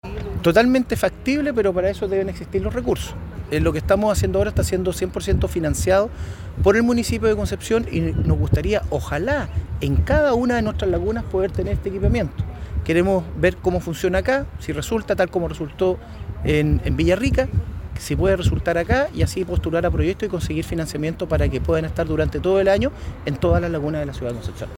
Por último, el alcalde Ortiz precisó que en el resto de las lagunas de la ciudad también “es factible” instalar estos dispositivos, aunque se requiere de un mayor financiamiento.